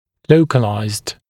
[‘ləukəlaɪzd][‘лоукэлайзд]локализованный, ограниченный определенным участком, локальный